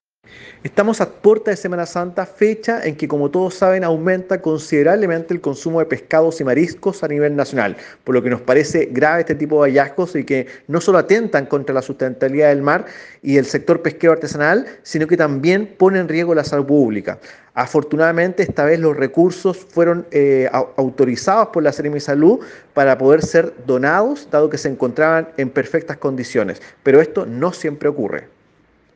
Cuna-Director-Regional-Sernapesca-Los-Rios.ogg